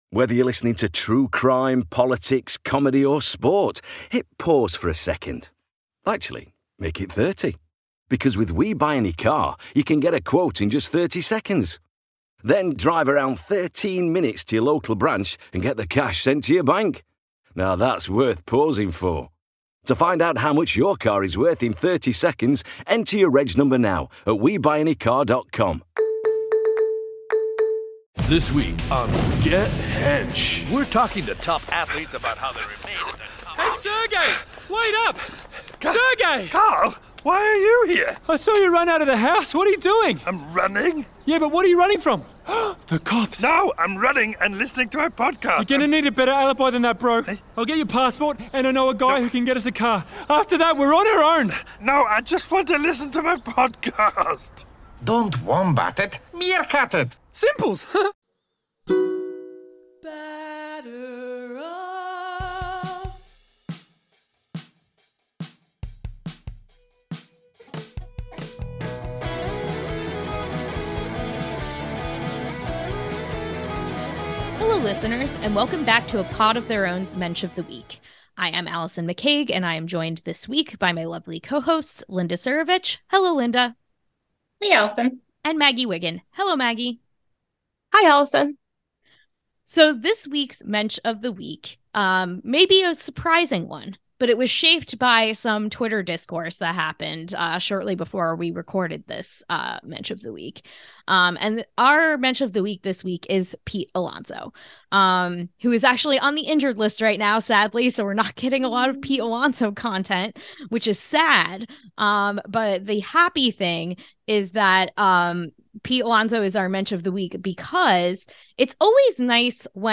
Welcome back to A Pod of Their Own, an all-women led Home Run Applesauce podcast where we talk all things Mets, social justice issues in baseball, and normalize female voices in the sports podcasting space.